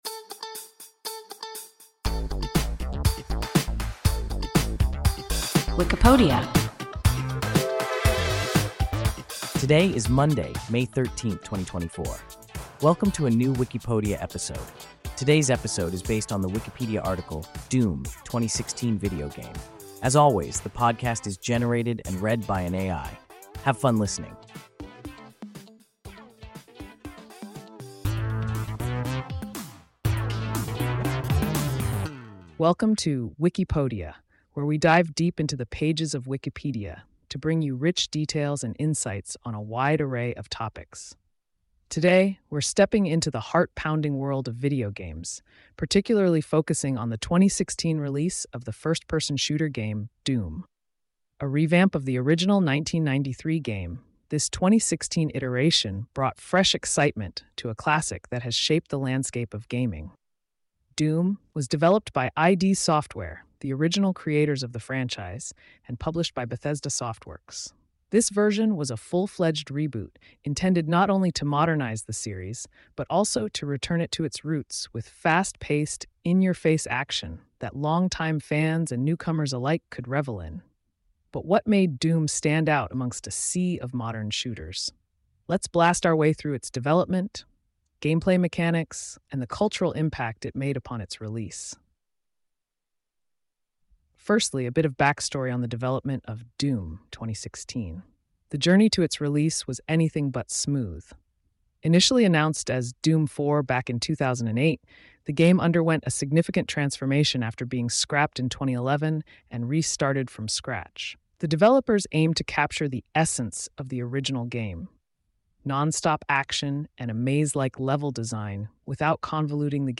Doom (2016 video game) – WIKIPODIA – ein KI Podcast